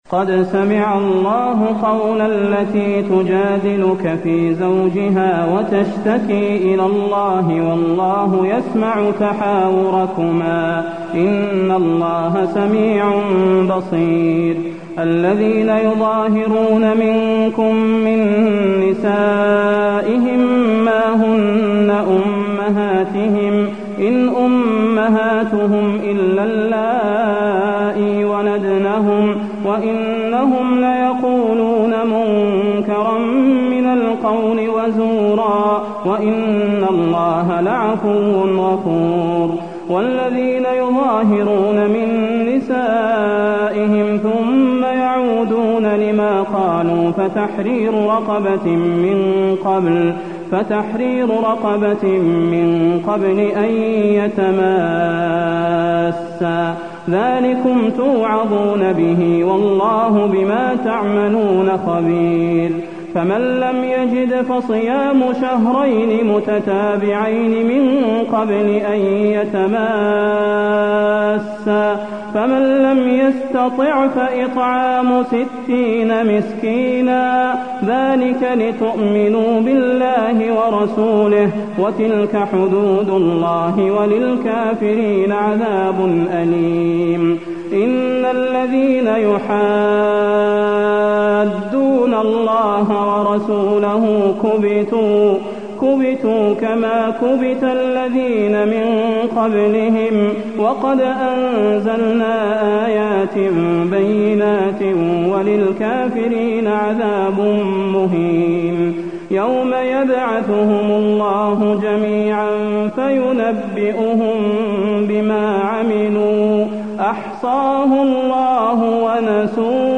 المكان: المسجد النبوي المجادلة